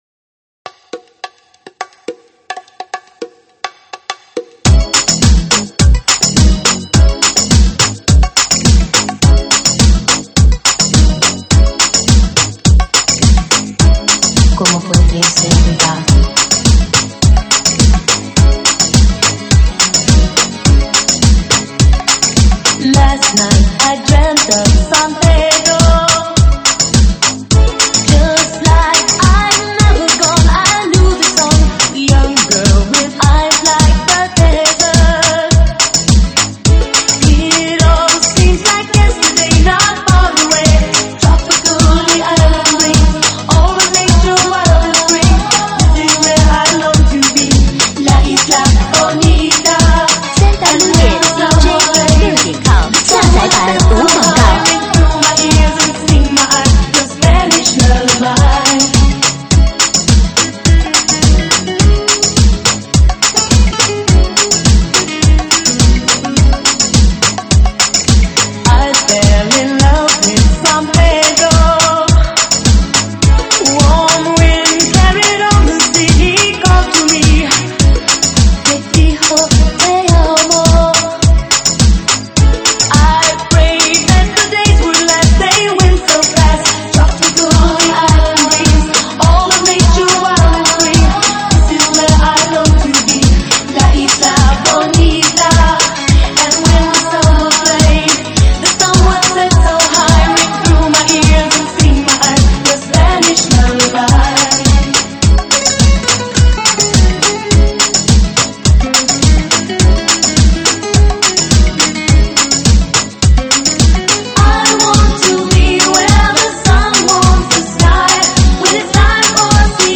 舞曲类别：吉特巴